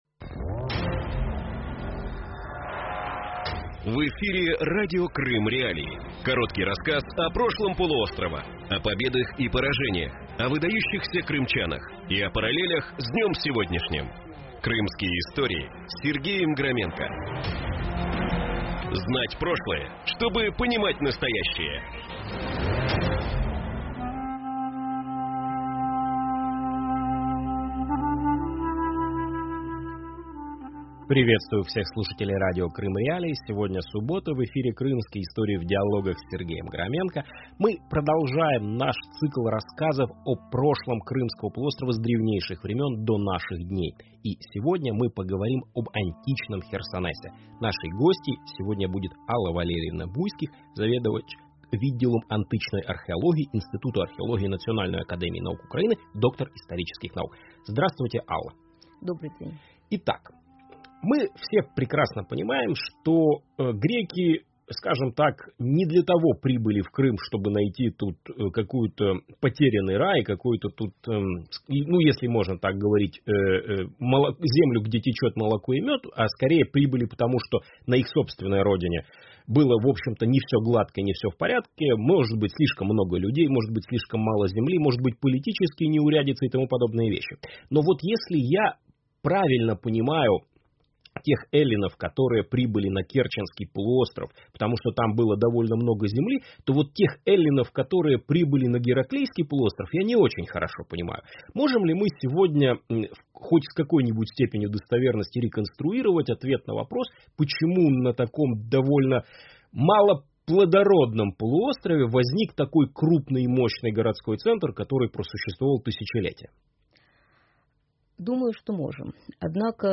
Новый цикл программы Крымские.Истории в диалогах рассказывает об истории Крыма с древнейших времен до наших дней.